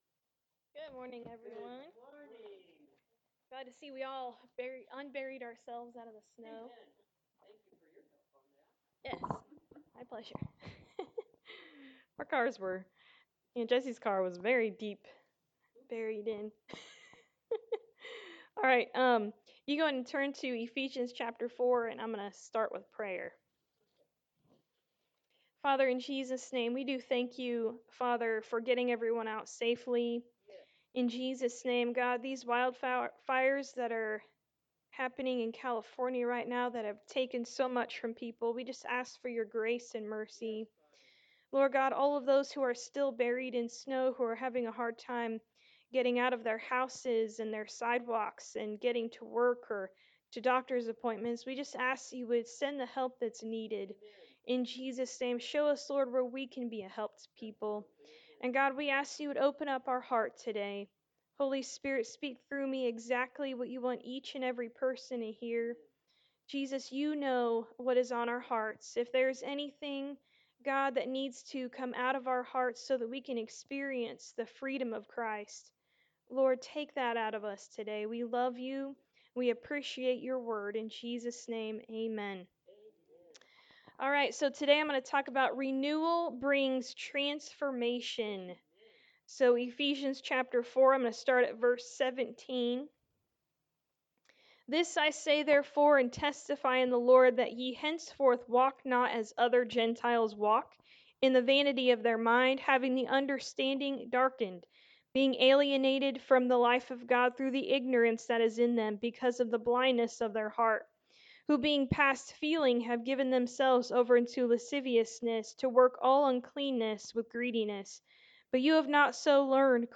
Romans 12:1-2 Service Type: Sunday Morning Service If you have believed in your heart that Jesus is Lord and confessed with your mouth